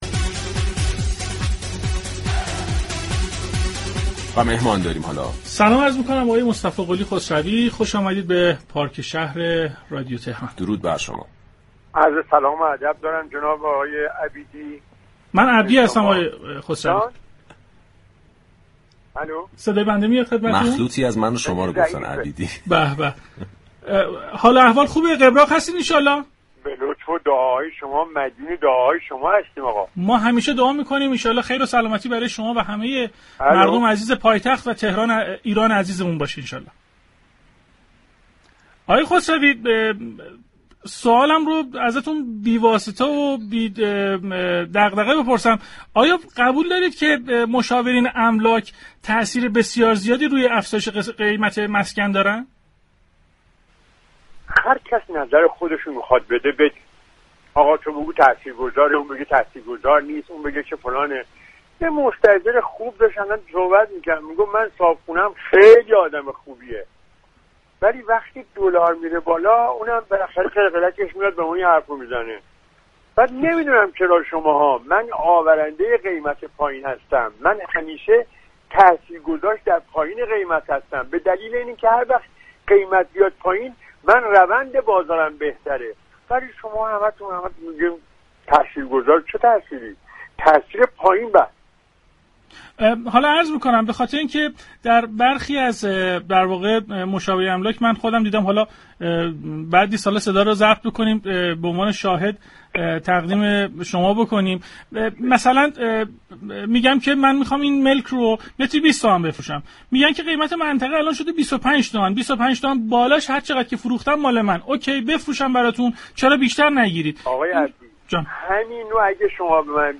در گفتگوی تلفنی